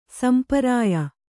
♪ samparāya